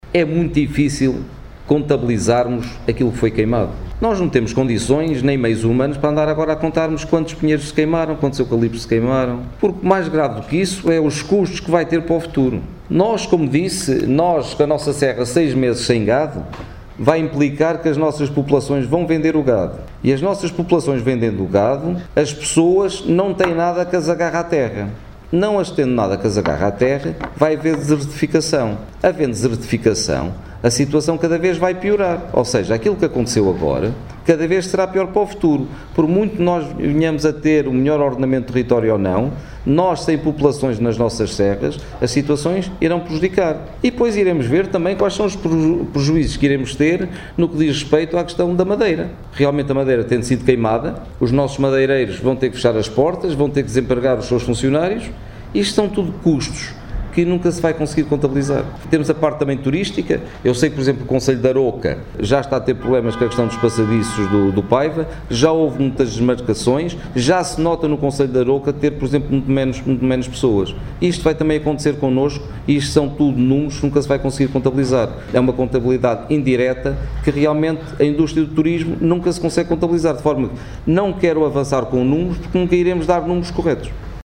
Declarações de Vítor Figueiredo, Presidente de S. Pedro do Sul, numa conferência de imprensa aos jornalistas hoje pelas onze da manhã.